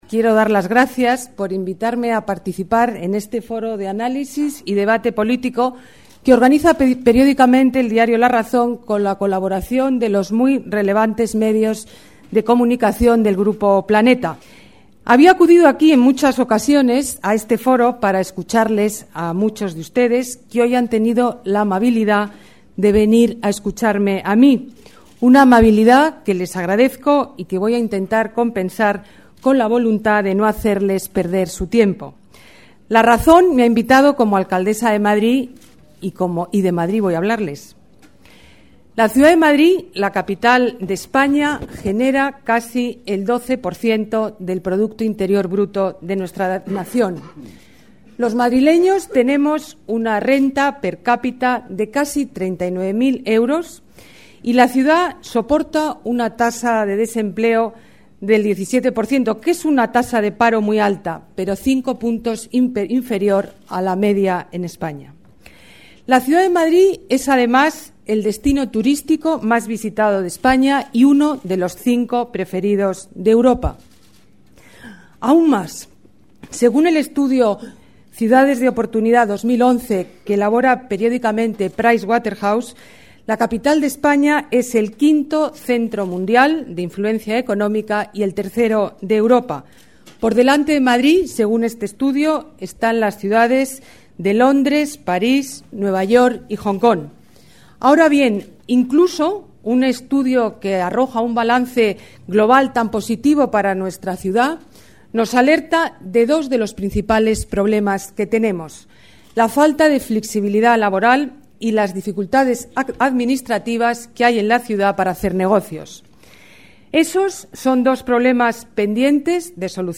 La alcaldesa de Madrid, Ana Botella, ha participado esta tarde en el foro de análisis y debate que organiza periódicamente el diario La Razón con la colaboración del Grupo Planeta.
Nueva ventana:Discurso de Ana Botella En La Razón